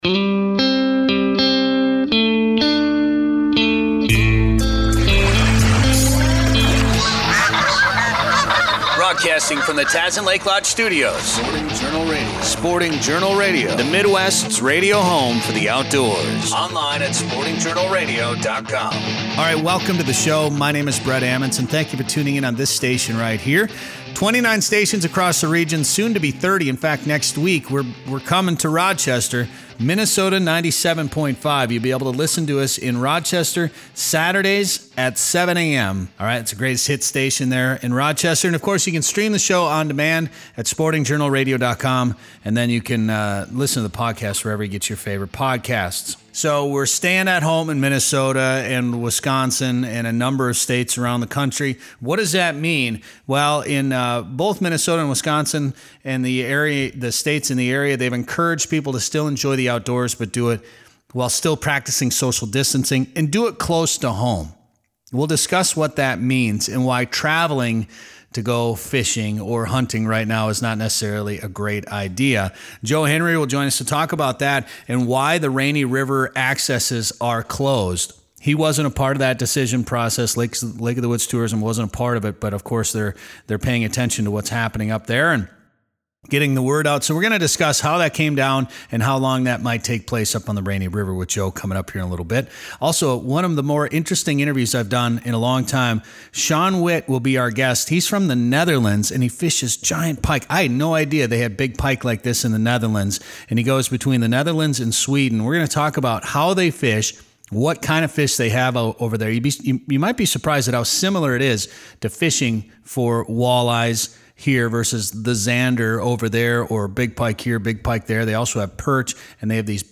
This is part one of our interview.